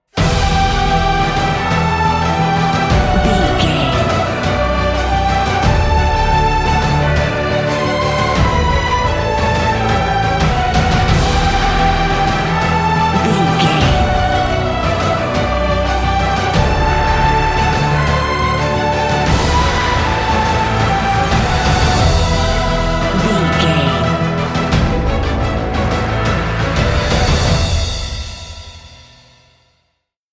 Epic / Action
Uplifting
Aeolian/Minor
DOES THIS CLIP CONTAINS LYRICS OR HUMAN VOICE?
WHAT’S THE TEMPO OF THE CLIP?
brass
choir
drums
strings
synthesizers